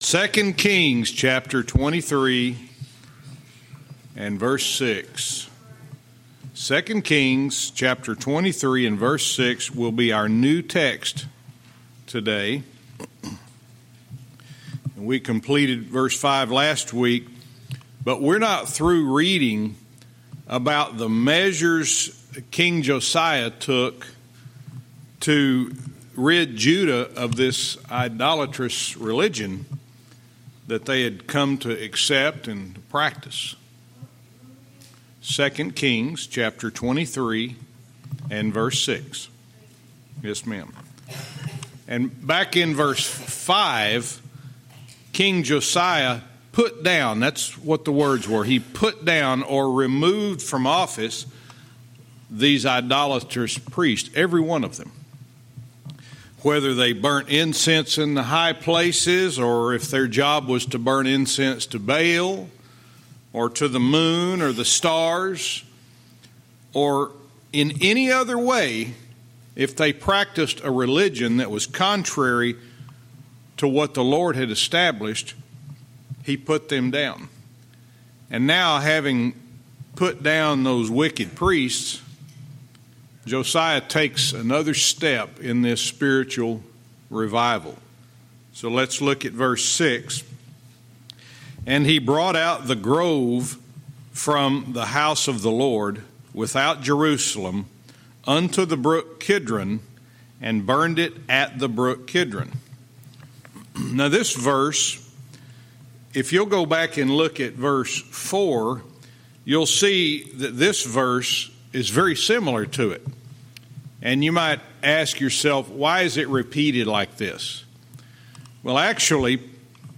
Verse by verse teaching - 2 Kings 23:6-7